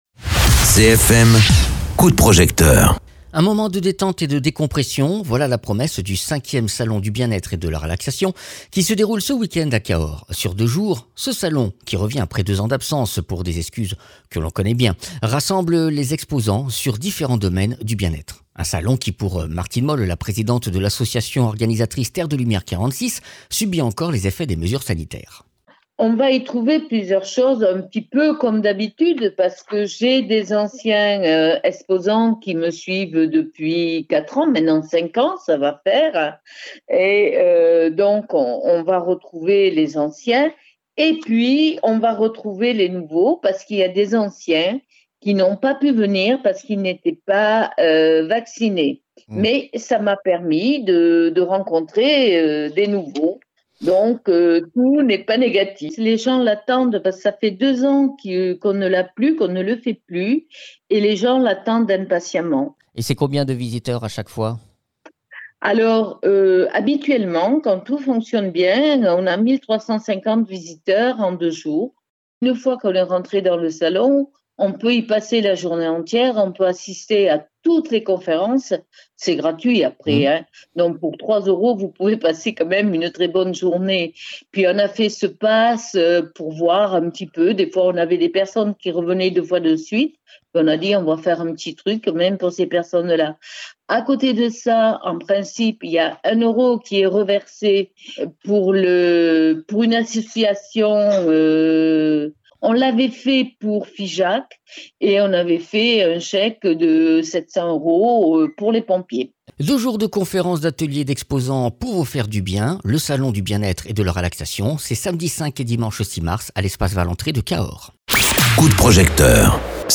Vous êtes dans le salon du bien-etre et de la relaxation, à Cahors.
Interviews